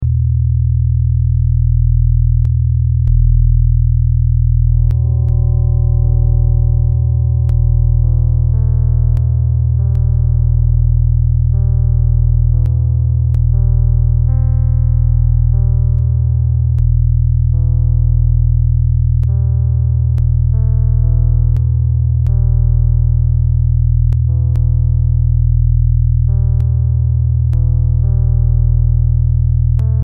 This Mp3 Sound Effect Struggling to think straight? This brown noise helped me shut out the fog.